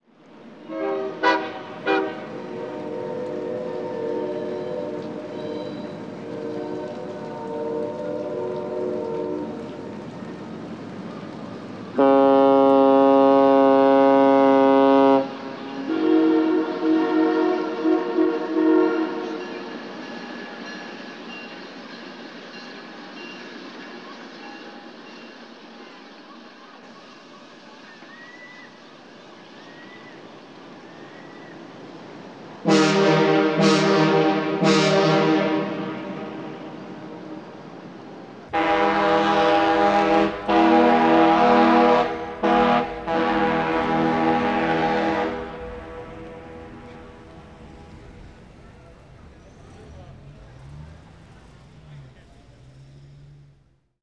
The Music of Horns and Whistles, from The Vancouver Soundscape
Signals.aif